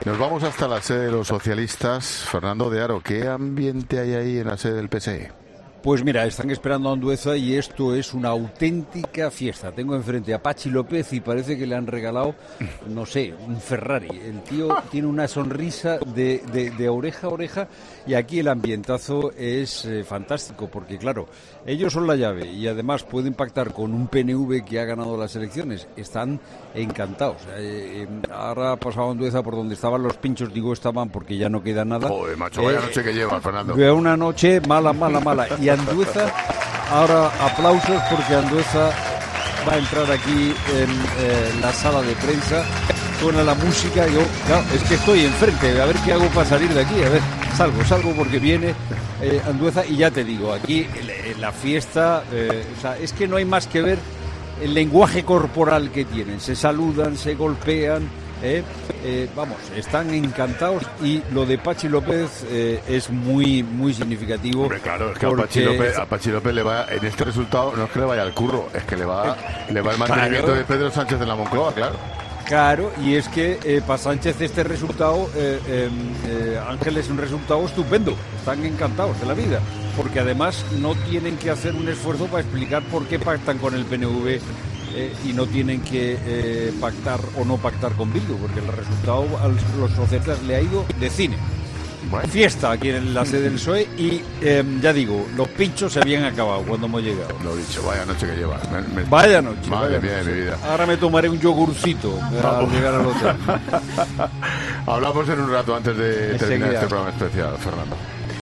desde la sede del PSE